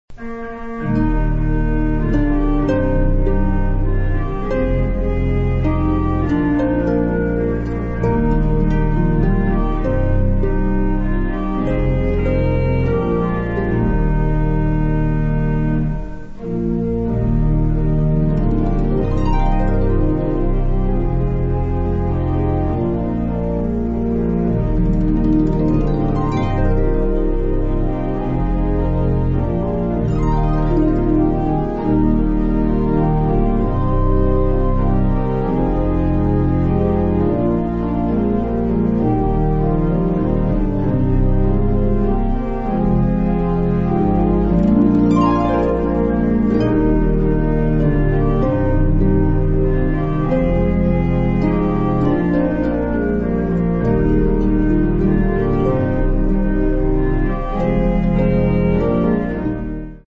harpist
Genre: Hymns, Praise and Gospel